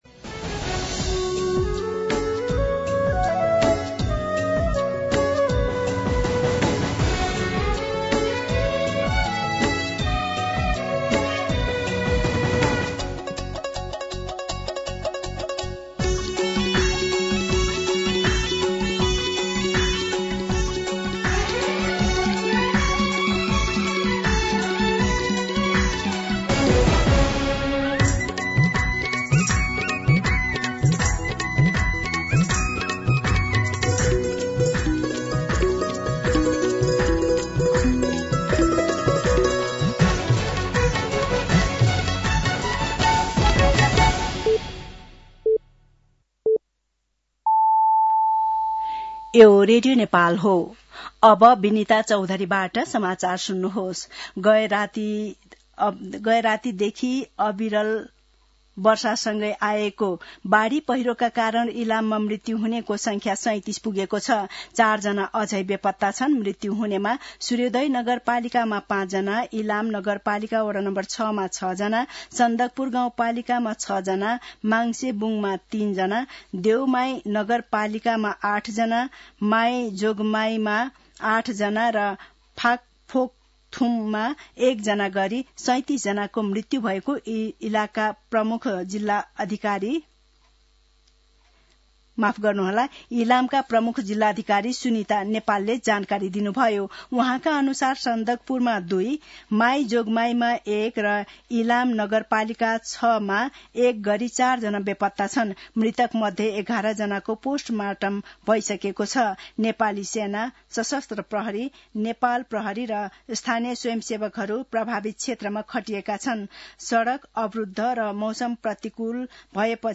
दिउँसो ४ बजेको नेपाली समाचार : १९ असोज , २०८२
4-pm-Nepali-News-1.mp3